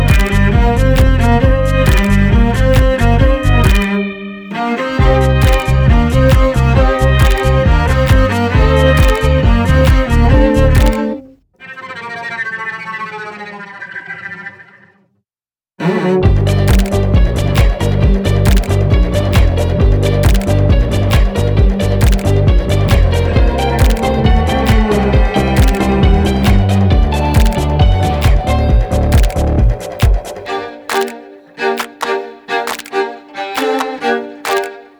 Жанр: Поп музыка / Рок / Инструмантальные
Pop, Rock, Instrumental